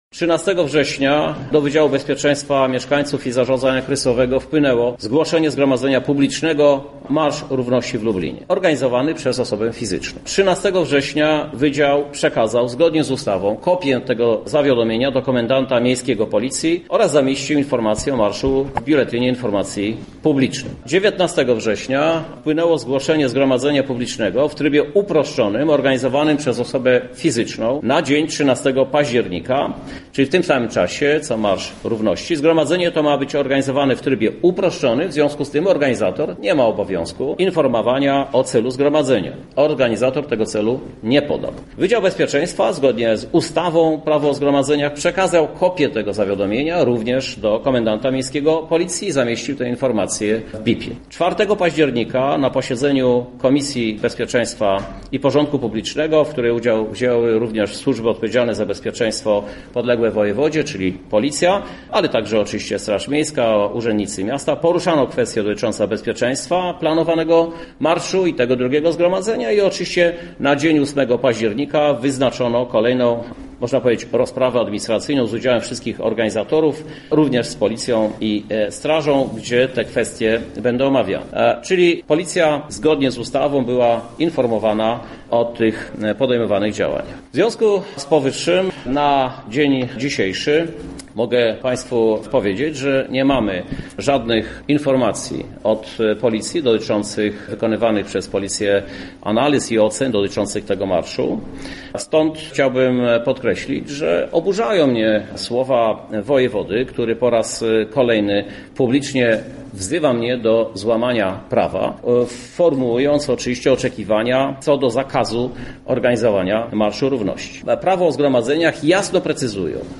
Krzysztof Żuk został zapytany na konferencji prasowej czy pojawi się na nadzwyczajnej sesji rady miasta.